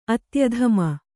♪ atyadhama